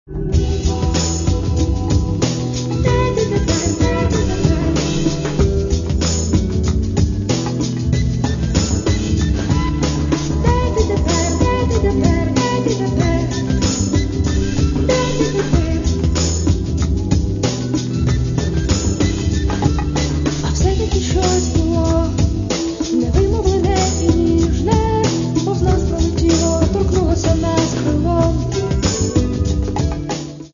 Каталог -> MP3-CD -> Альтернатива